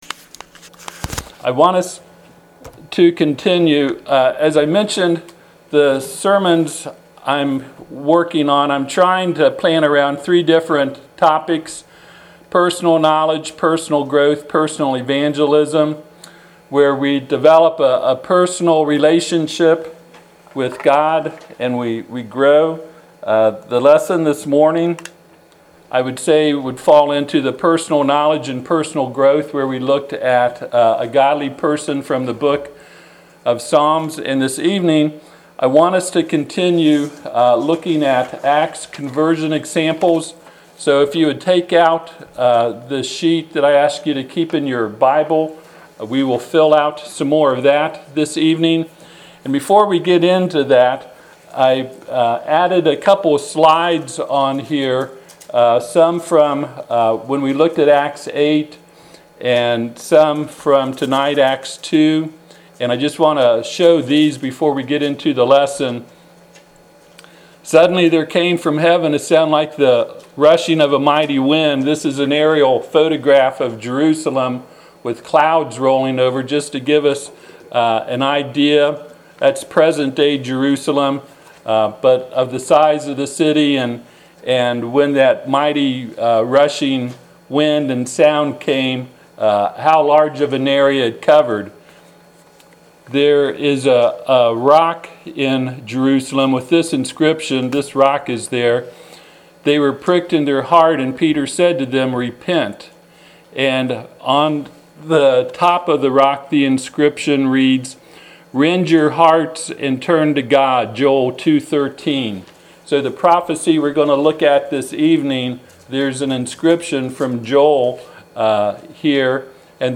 Preacher
Passage: Acts 2 Service Type: Sunday PM